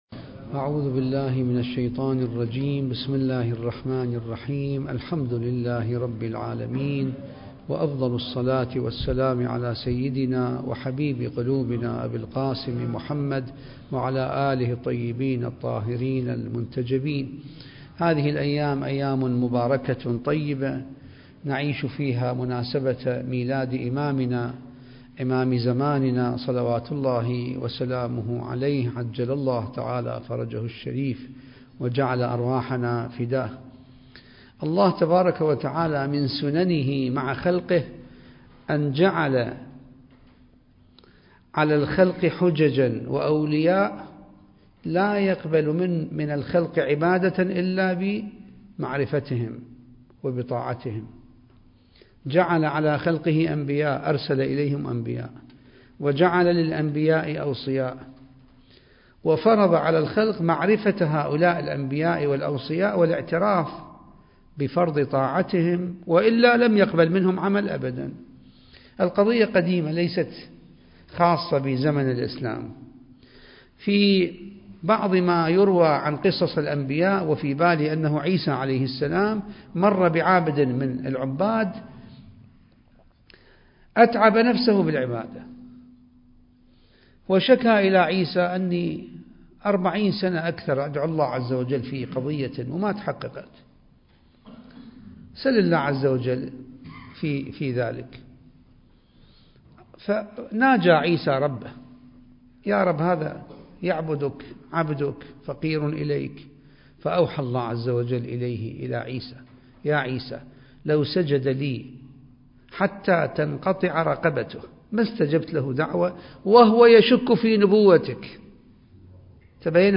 المكان: جامع الصاحب (عجّل الله فرجه) - النجف الأشرف التاريخ: 2021